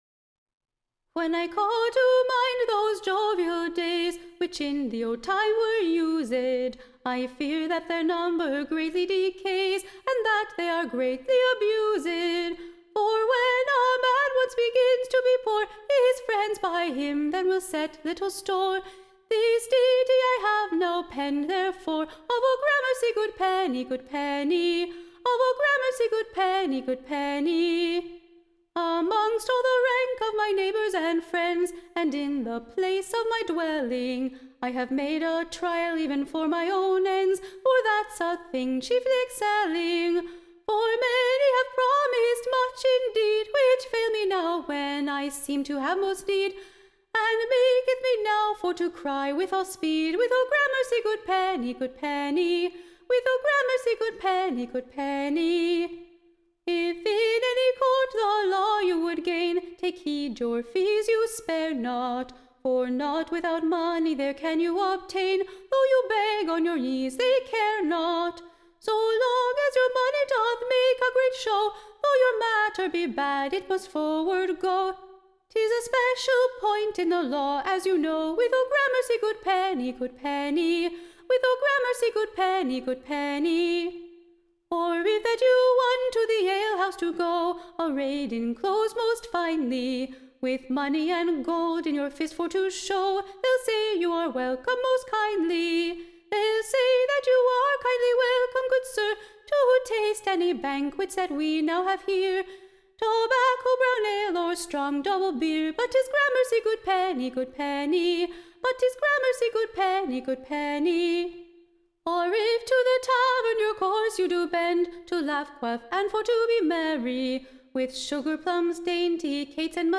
Recording Information Ballad Title Oh Gramercy Penny: / Being a Lancashire Ditty, and chiefly penn'd, / To proue that a penny is a mans best friend.